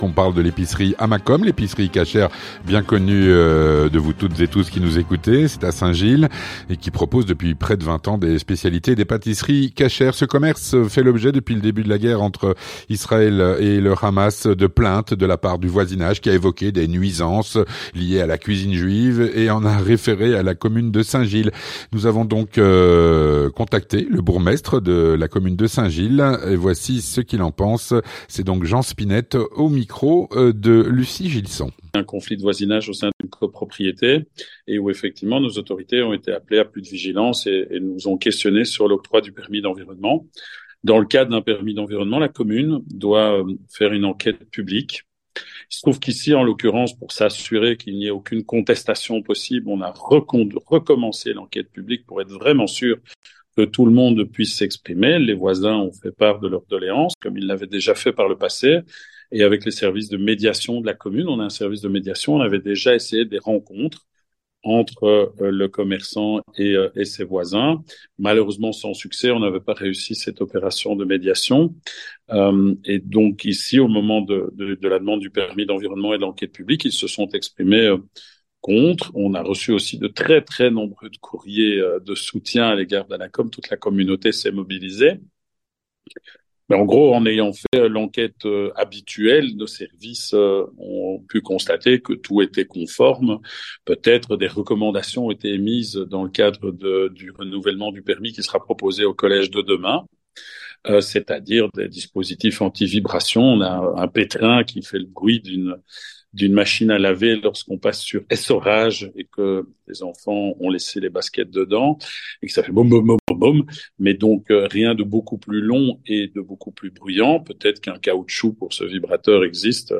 Avec Jean Spinette, Bourgmestre de la commune de Saint-Gilles